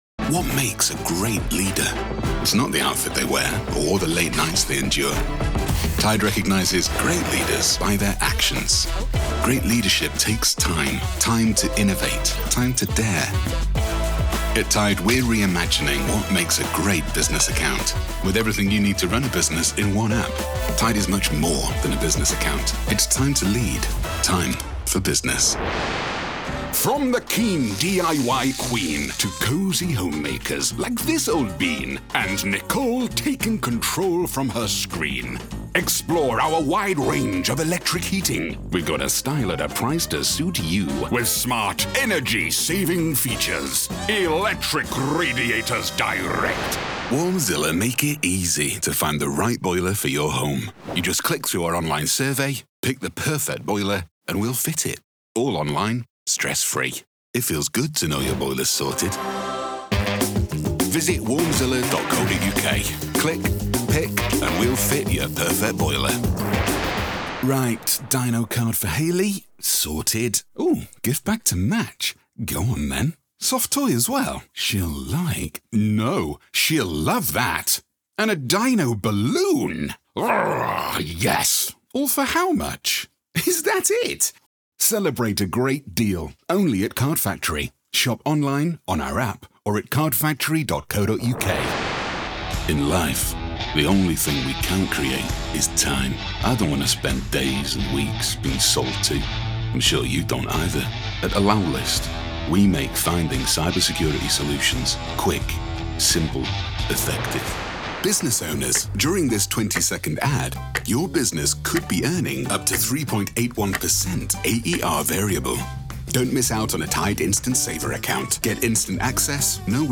From Northern accent to well spoken RP and everything in between
TV & Commercial
VOICE-REEL-TV-COMMERCIAL-17.9.25.mp3